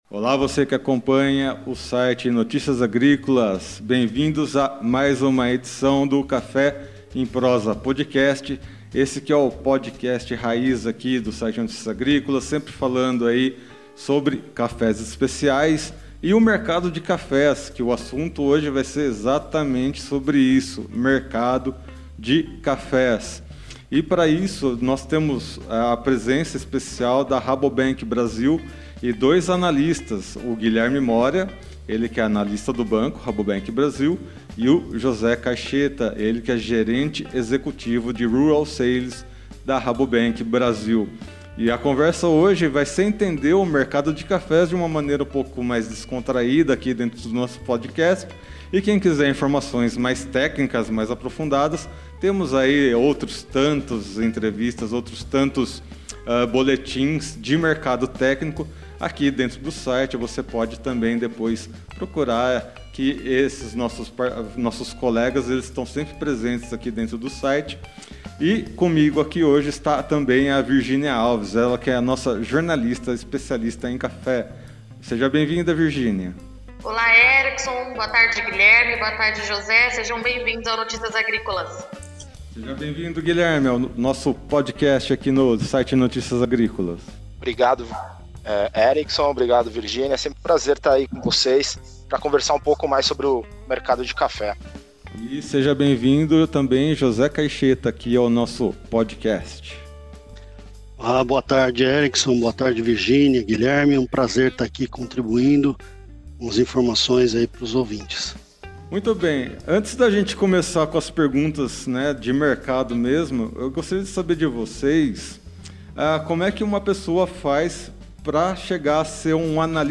Em conversas descontraídas, essas pessoas contarão suas histórias e trarão suas ligações com essa bebida que é uma das mais apreciadas no mundo todo.